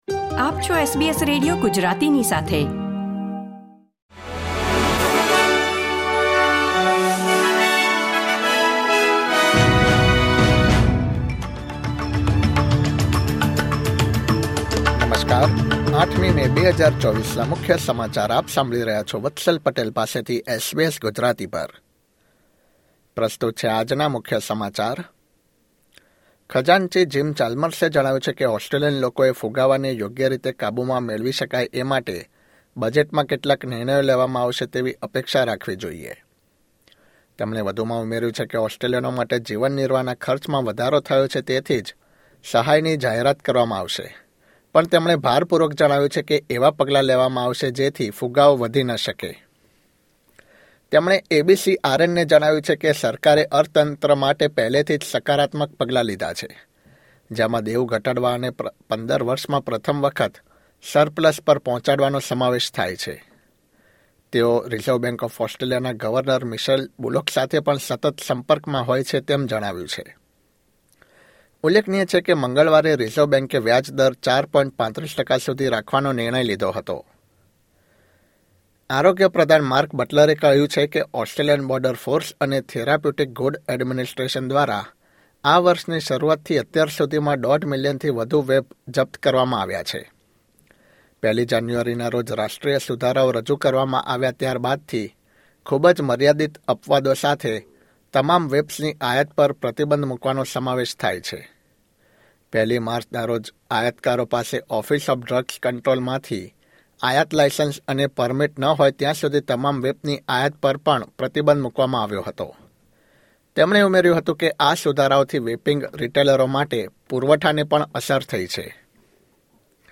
SBS Gujarati News Bulletin 8 May 2024